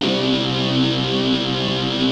POWER GUITAR 1.wav